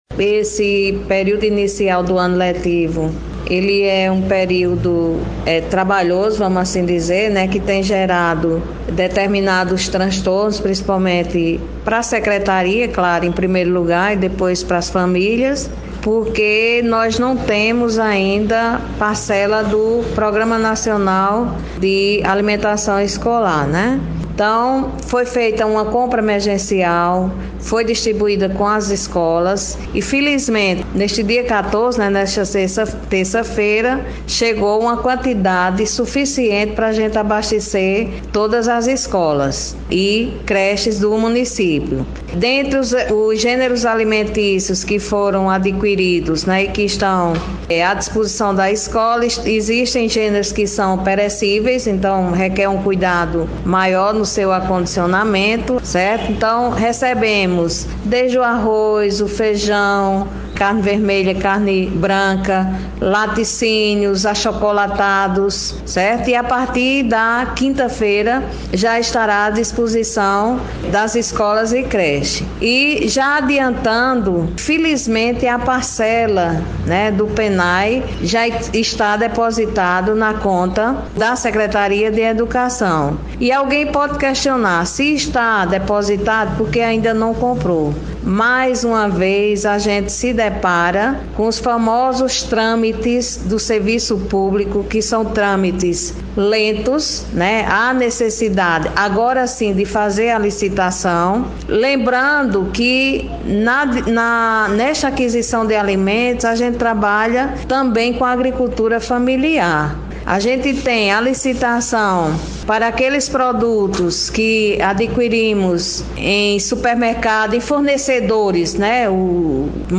Fala da secretária de Educação, Alana Candeia